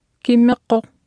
Below you can try out the text-to-speech system Martha.